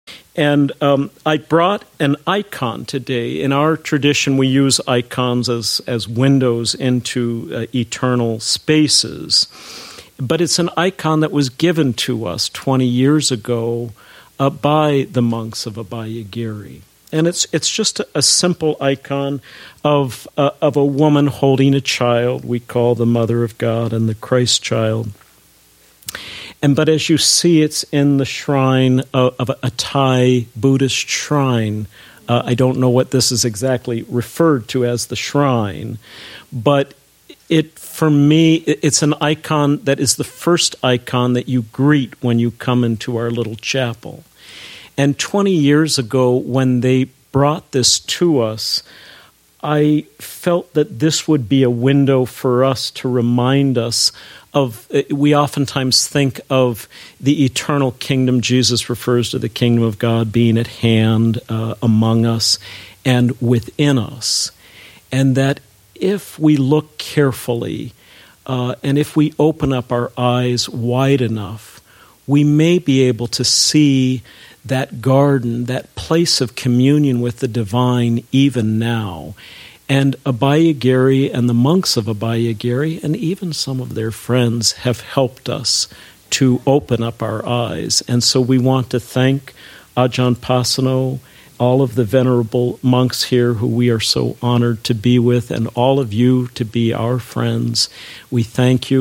Devotional practice / Objects of veneration / Icons 1 excerpt, 1:39 total duration Abhayagiri's 20th Anniversary , Session 6 – Jun. 4, 2016 Download audio (1:39) 5.